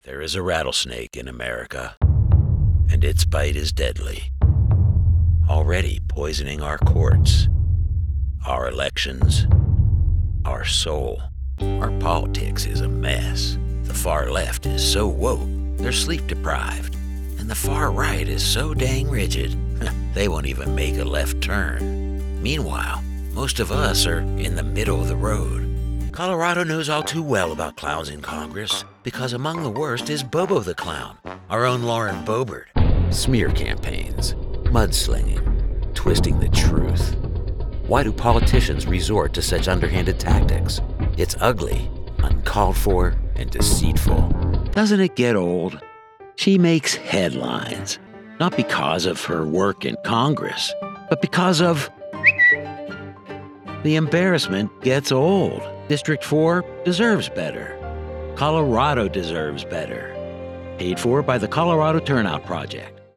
Adult, Mature Adult
Has Own Studio
My voice-overs are like smooth gravel; deep, textured, and unforgettable.
standard us | natural
political
husky